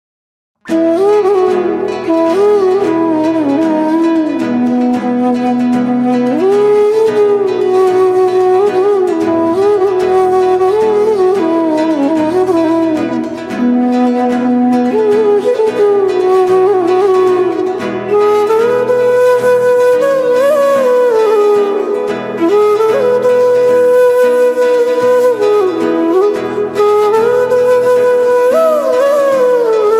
Drift into a tranquil reverie